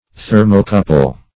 Thermocouple \Ther"mo*cou`ple\, n.